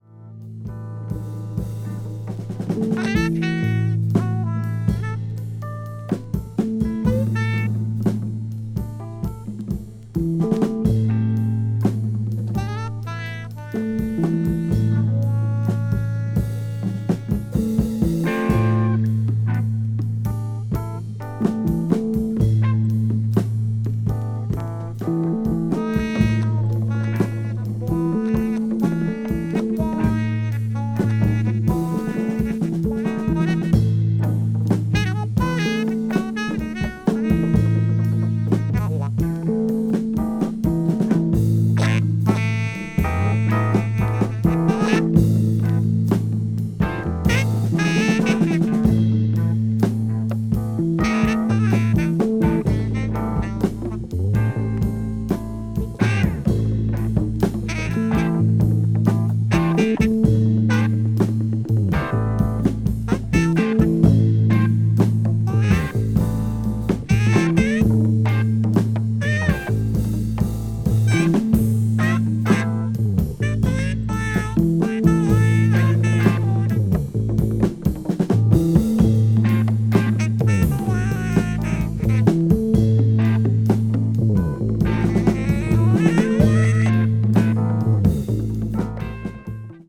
blues rock   crossover   jazz rock   psychedelic rock